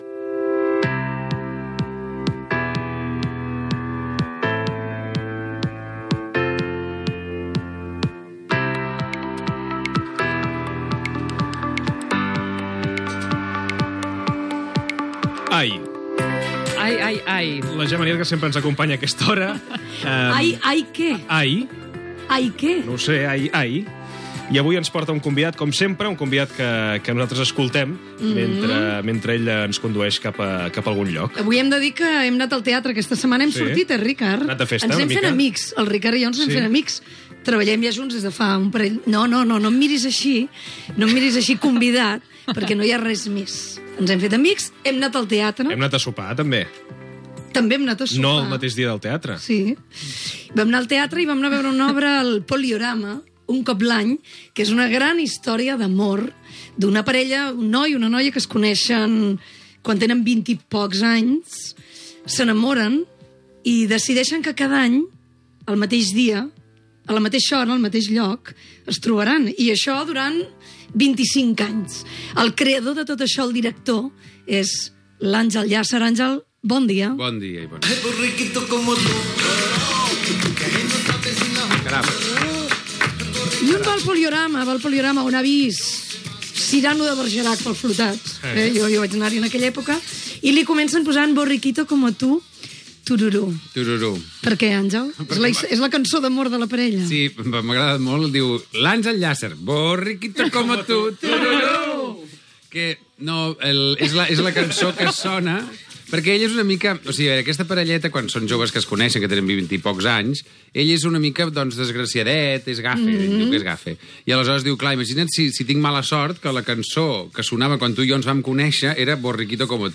Entrevista a Àngel Llàcer, director del muntatge de l'obra "Un cop l'any" al Teatre Poliorama de Barcelona sobre aquesta obra i la seva joventut
Entreteniment